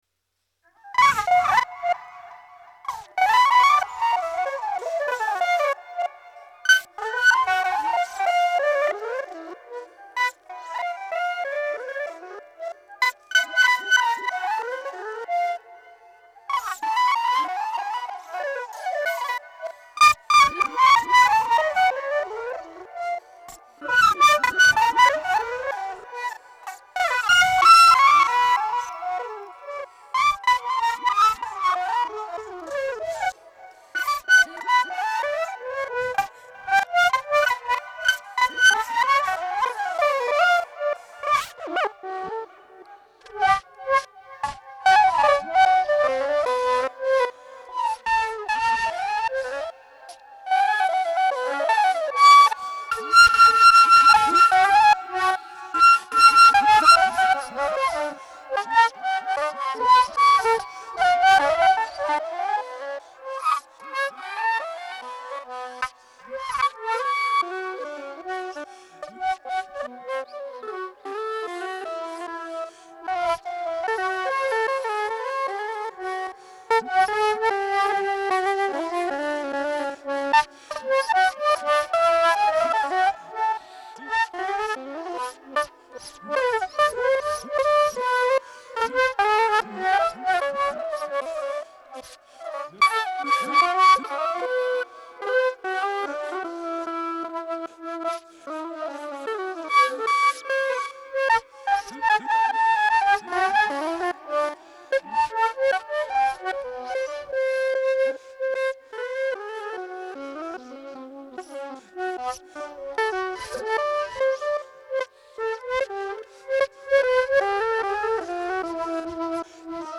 L’audio è la sintesi di un intervento di due ore.